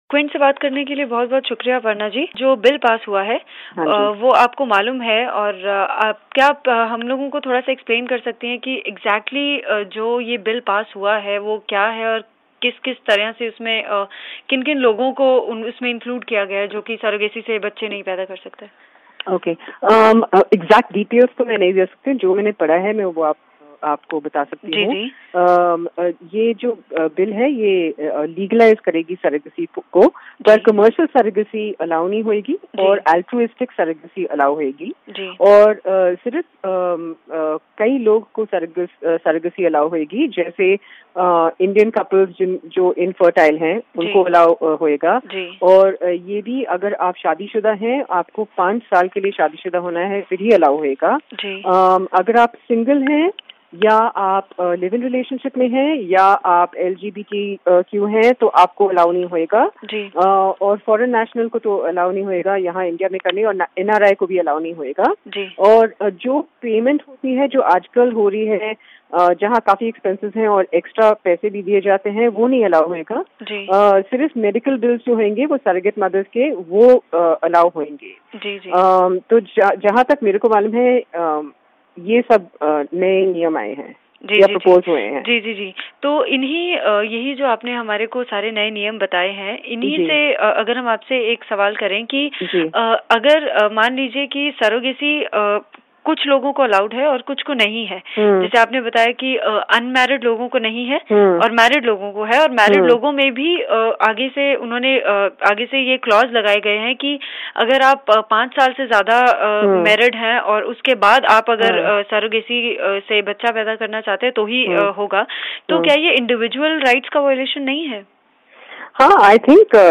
सरोगेसी पर खास बातचीत